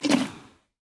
哞菇：进食
玩家喂食棕色哞菇时随机播放这些音效
Minecraft_mooshroom_eat4.mp3